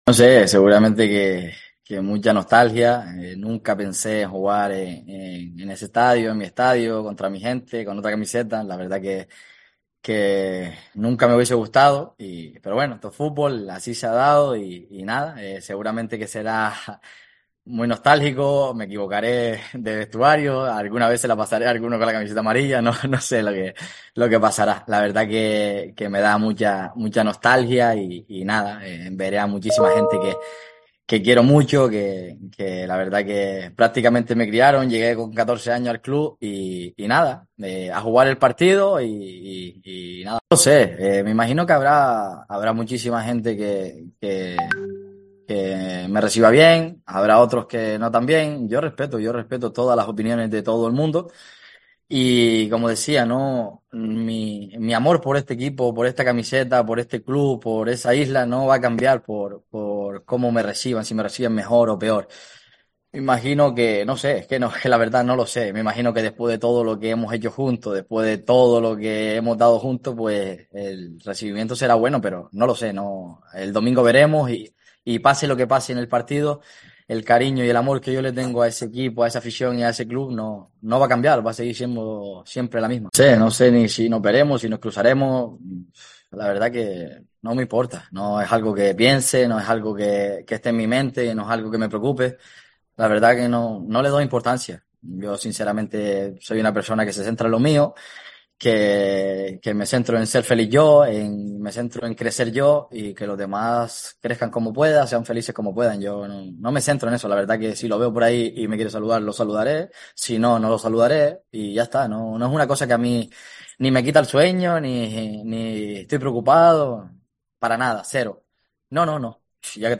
AUDIO: jonathan Viera habló para los medios de comunicación de la UD Las Palmas en rueda de prensa